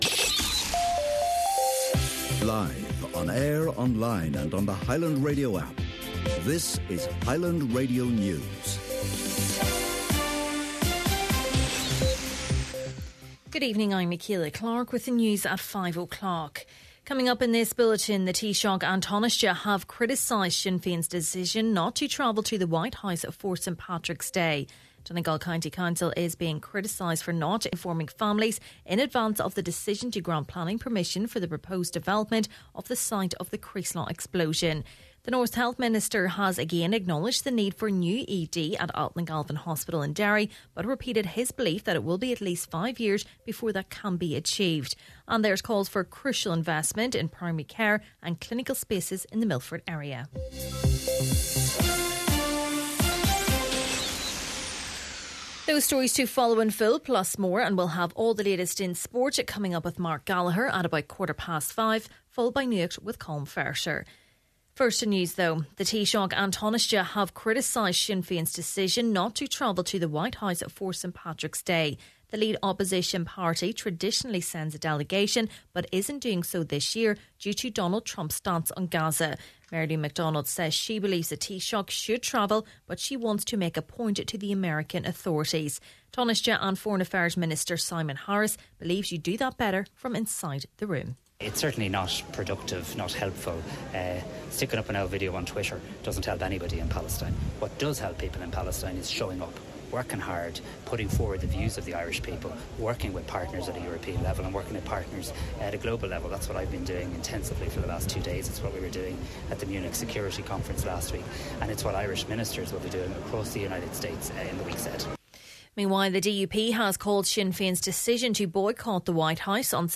Main Evening News, Sport, Nuacht and Obituaries – Friday, February 21st